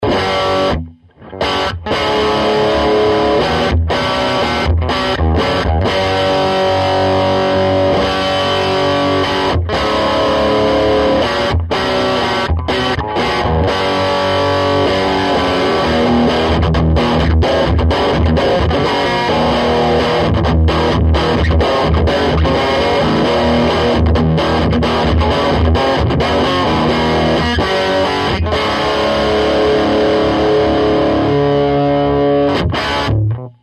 Used an SM57. No changes to tone controls just switched between rectifiers.
Lots of crunch and nice tone. The clips are full vol on the bright channel, MV at about 30%. Lead II with Carvin M22SD humbucker, single vol, no tone control.
Tube (Dry)
I think the tube has a little more robust low-end, but it's hard to tell, even using decent in-ear 'phones.